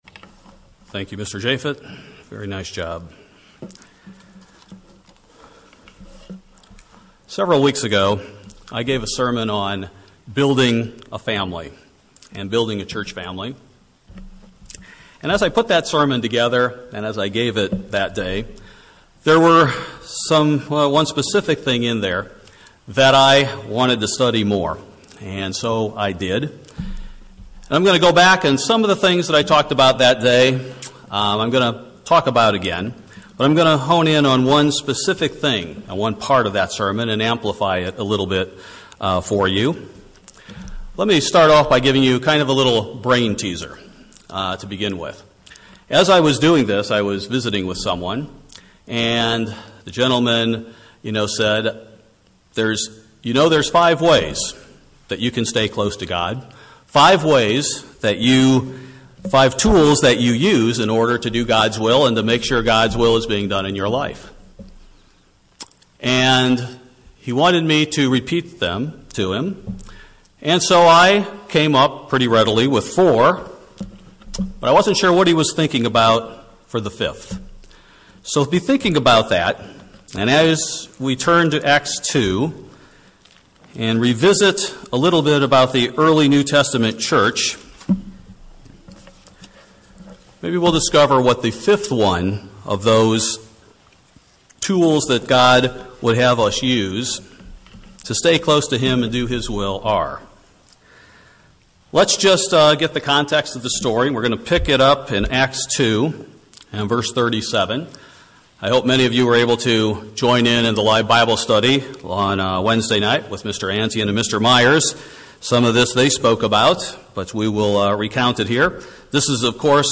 Find out more by listening to this sermon entitled "Devoted to Fellowship"